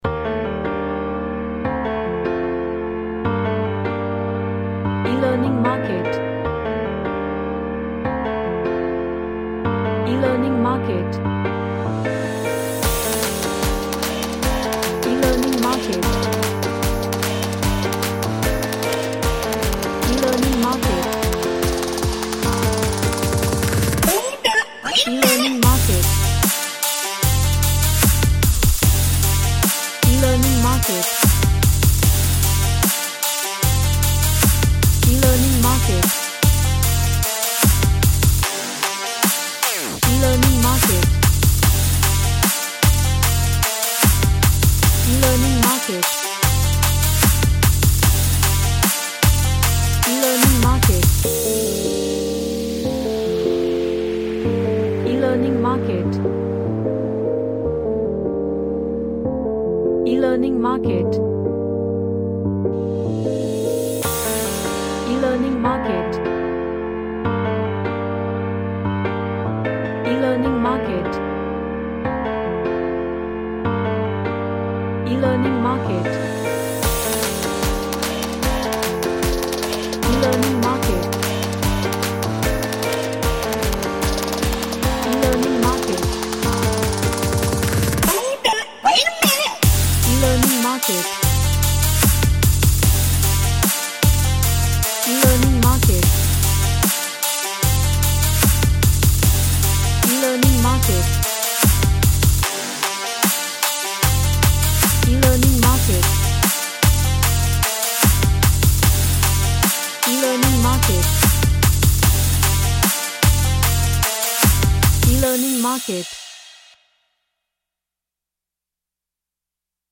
Attacking Brass, with deep Basses
Sci-Fi / Future